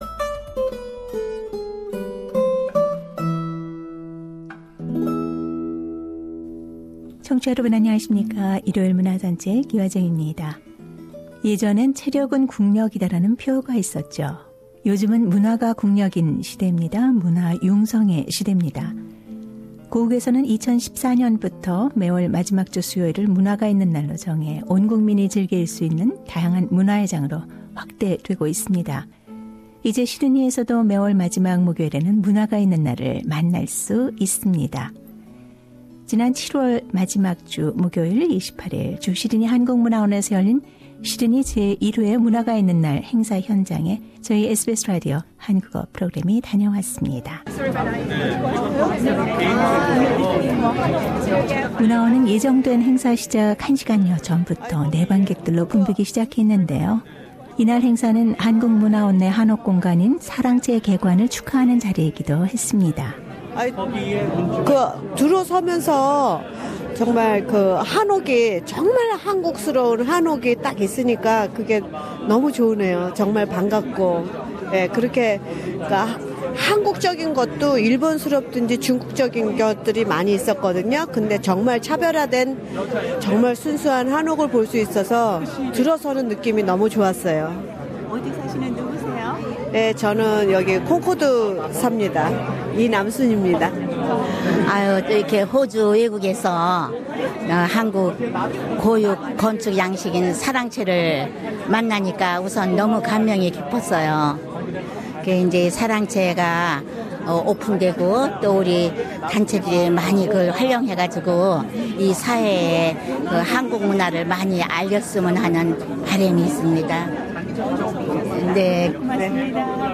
SBS Radio 한국어 프로그램이 제 1회 문화가 있는 날 행사 현장 소식을 전한다.